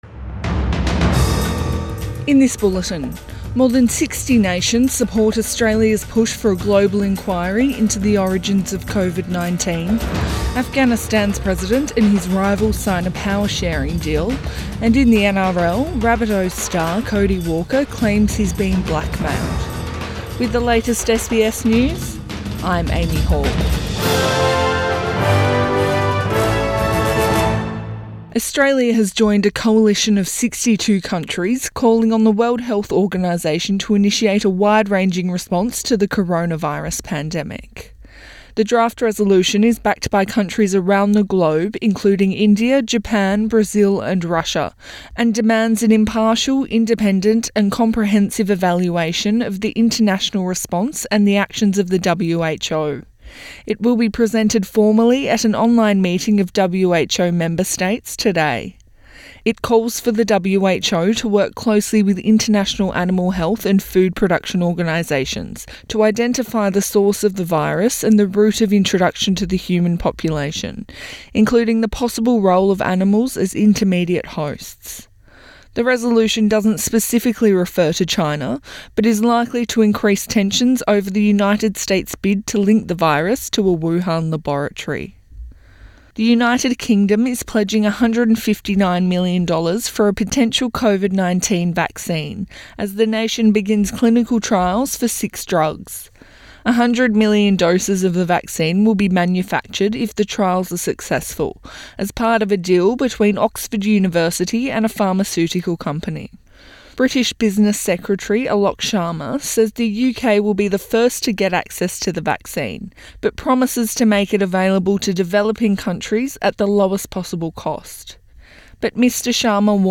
AM bulletin 18 May 2020